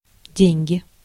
Ääntäminen
IPA: /ˈdʲenʲɡʲɪ/